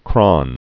(krŏn)